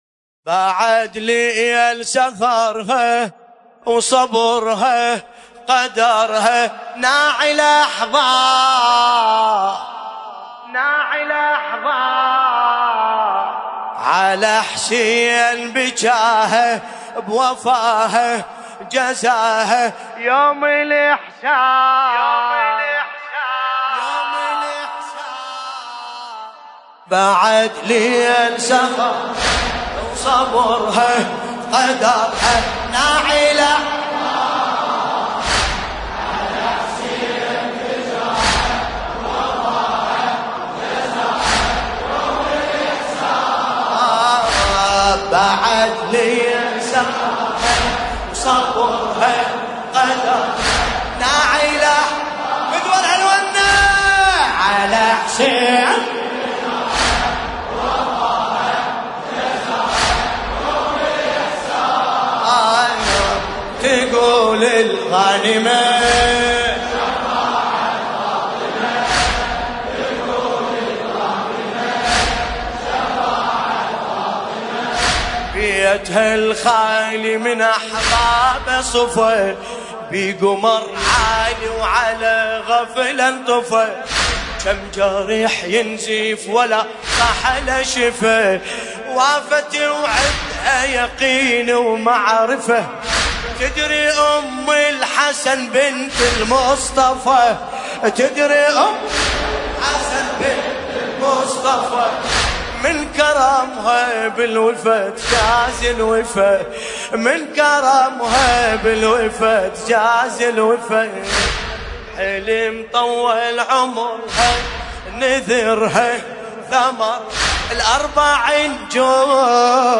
ملف صوتی بعد ليل بصوت باسم الكربلائي
الرادود : الحاج ملا باسم الكربلائي المناسبة : وفاة ام البنين 1440 المكان : العتبة العباسية المقدسة - كربلاءليلة 12 جمادى الآخره 1440 هـ